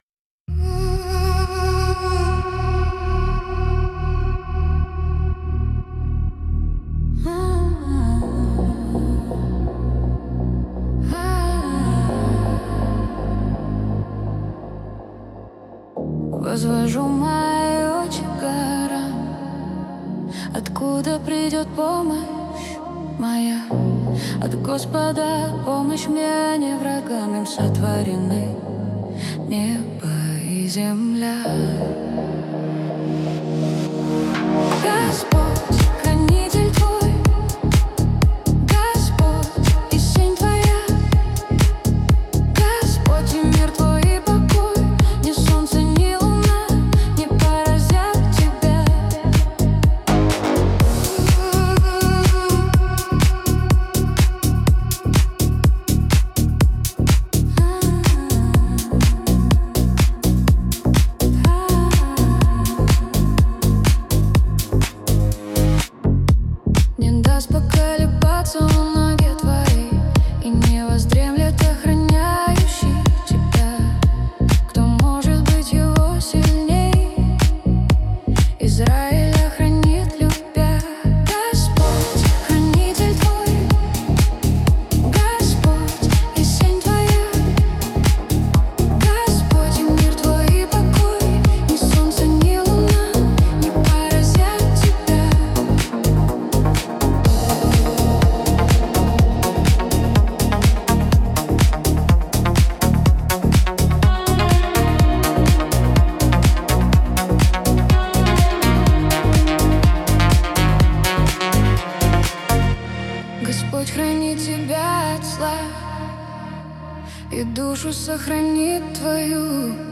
песня ai
10 просмотров 55 прослушиваний 1 скачиваний BPM: 126